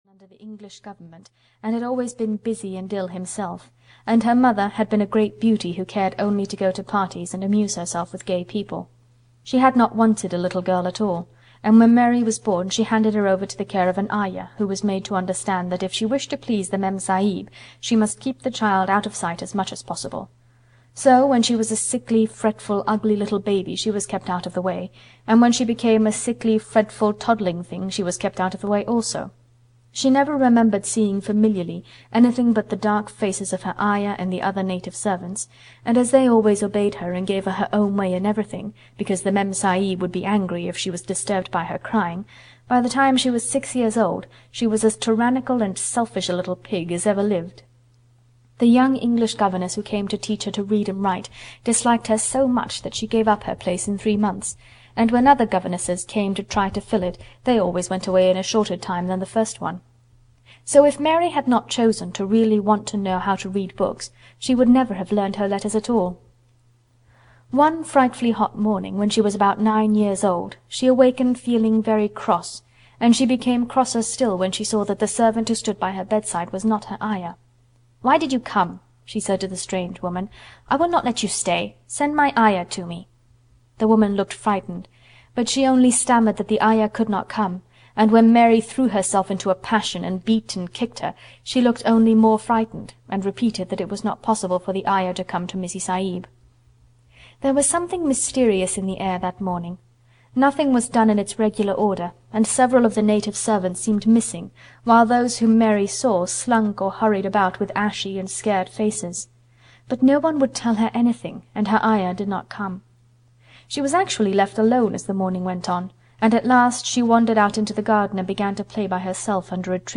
The Secret Garden (EN) audiokniha
Ukázka z knihy